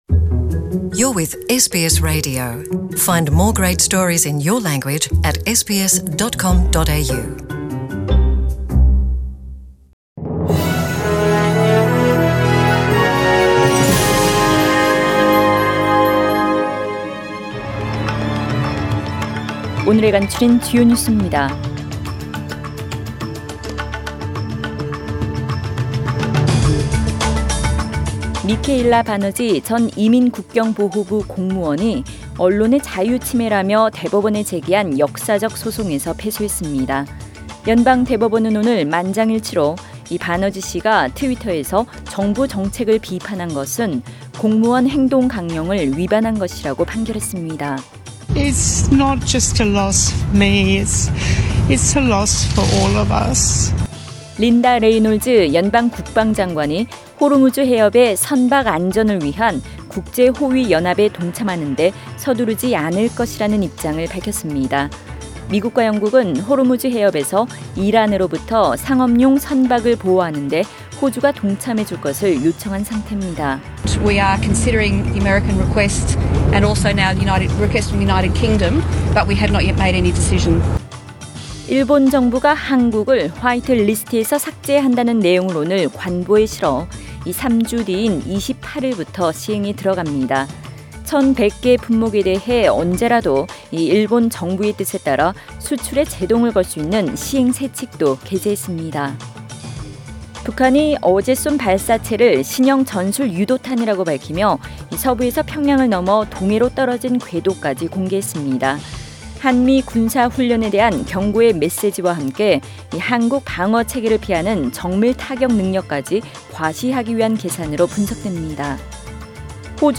SBS 한국어 뉴스 간추린 주요 소식 – 8월 7일 수요일
2019년 8월 7일 수요일 저녁의 SBS Radio 한국어 뉴스 간추린 주요 소식을 팟 캐스트를 통해 접하시기 바랍니다.